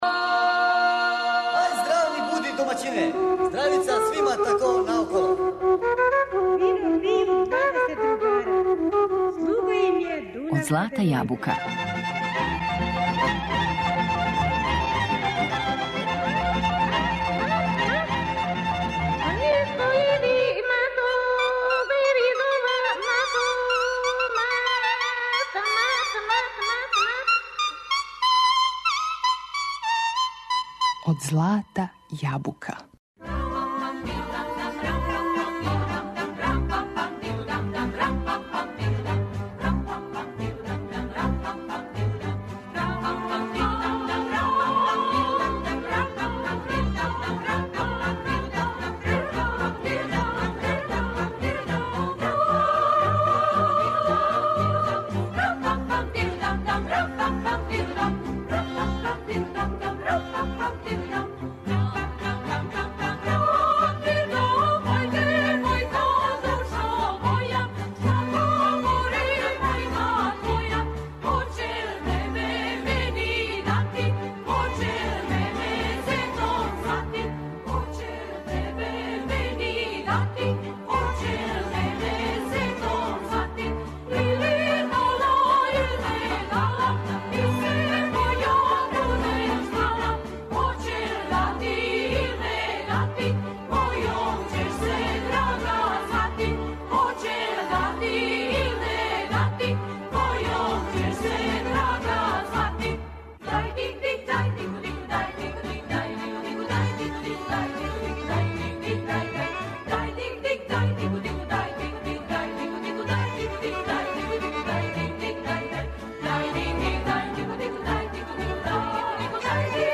Две емисије 'Од злата јабука' посветили смо женској певачкој групи 'Шумадија'.
Певали су песме из записа, народне, градске и компоноване и неговали су веома широк репертоар целе некадашње Југославије.